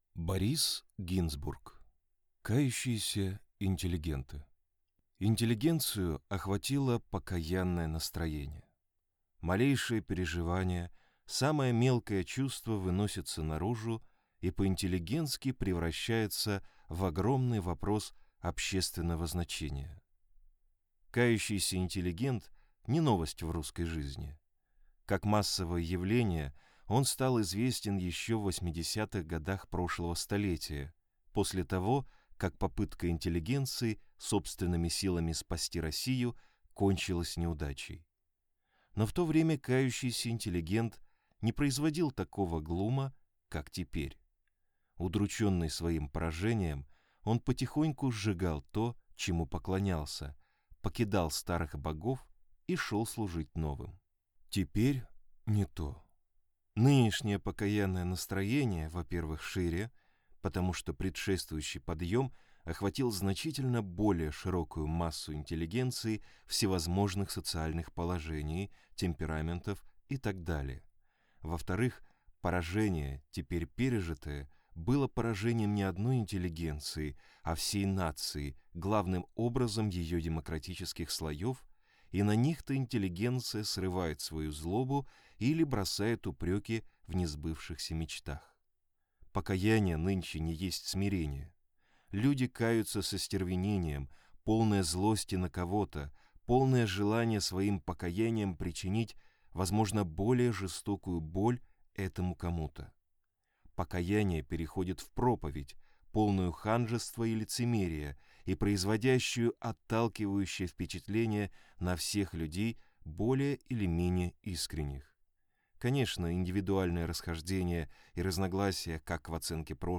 Аудиокнига Кающиеся интеллигенты | Библиотека аудиокниг